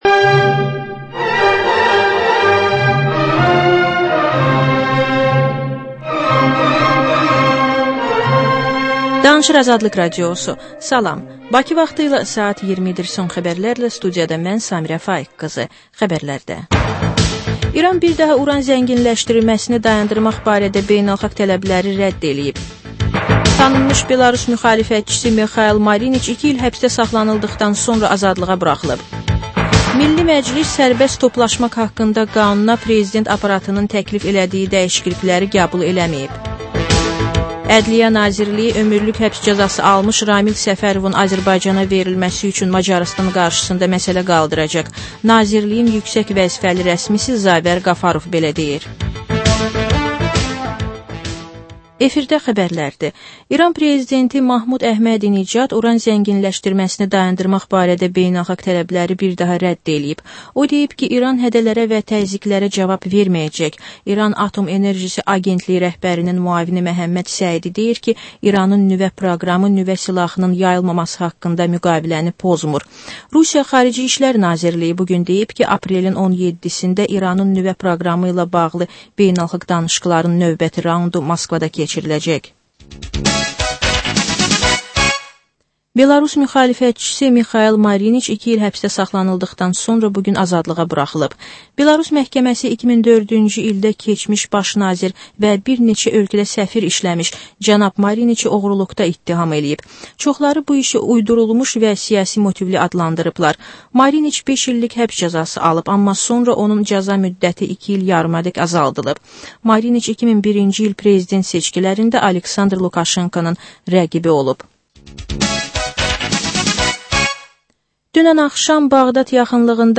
Xəbərlər
Xəbərlər, reportajlar, müsahibələr.